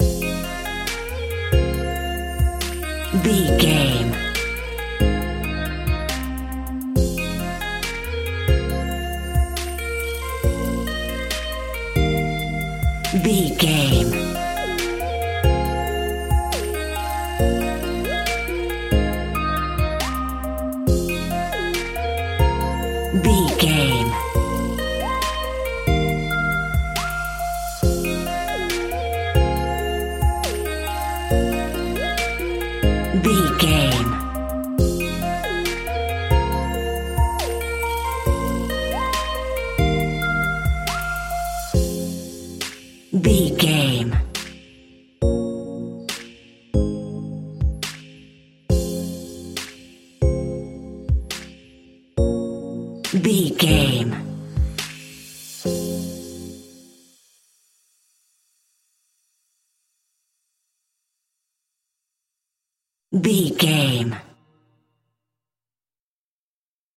Aeolian/Minor
Slow
hip hop
chilled
laid back
hip hop drums
hip hop synths
piano
hip hop pads